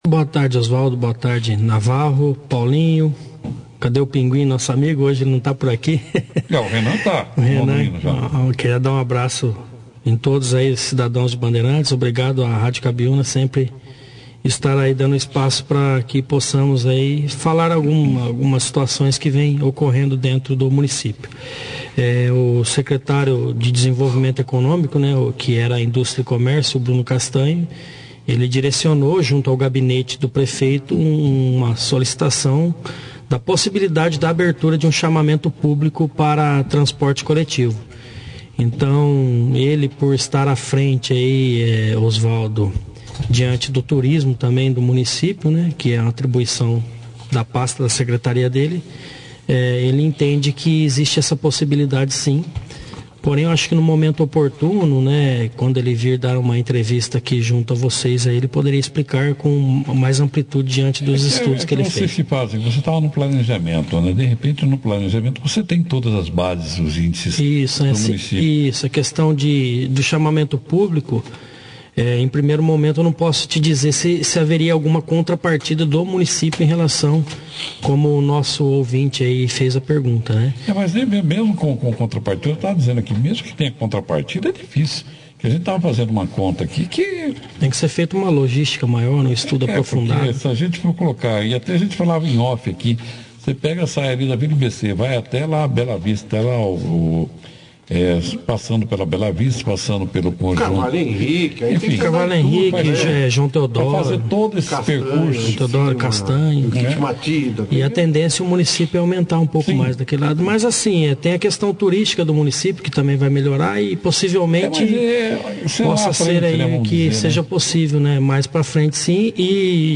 O secretário de Planejamento de Bandeirantes, Daniel Gustavo Silva (Babão), (foto), que está deixando o planejamento e assumindo a Secretaria de Governo da administração municipal, participou da 2ª edição do jornal Operação Cidade desta terça-feira, 31/01, fazendo um breve relato dos trabalhos realizados em seu mandado e das atribuições agora na nova secretaria.